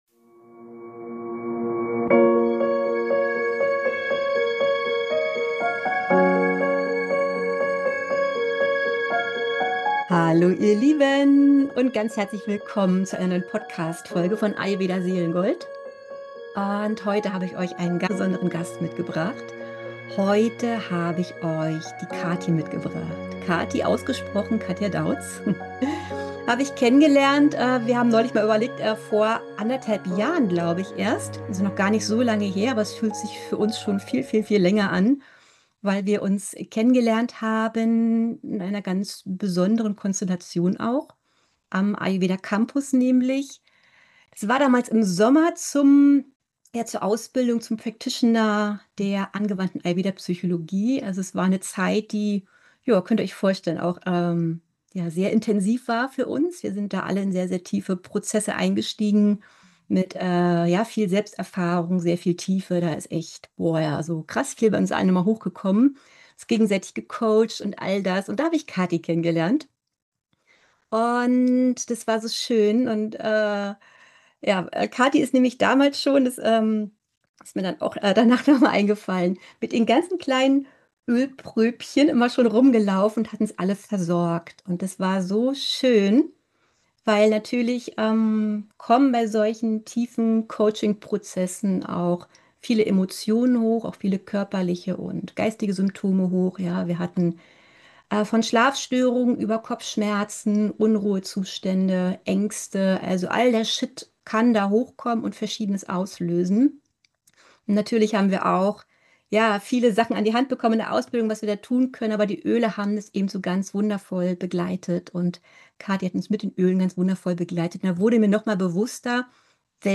Wenn Düfte die Seele berühren: Ayurveda, Emotionen und Ätherische Öle – im Interview